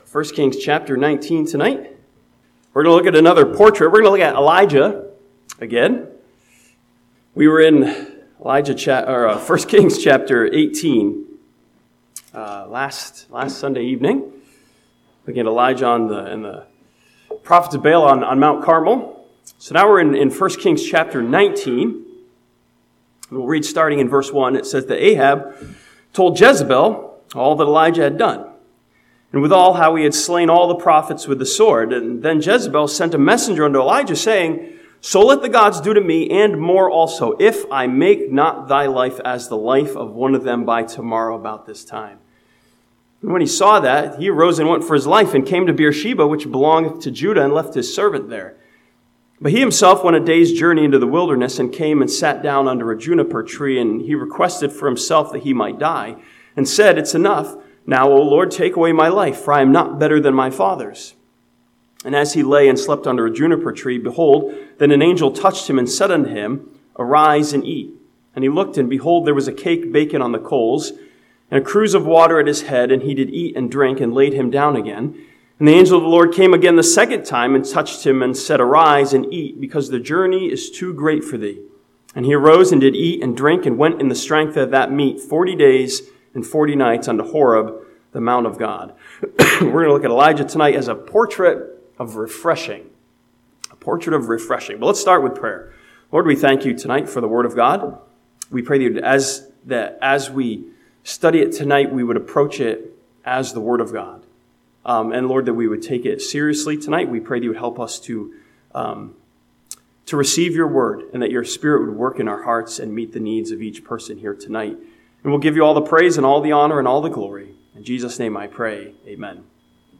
This sermon from 1 Kings chapter 19 looks at the ways in which the Lord took care of Elijah as a portrait of refreshing.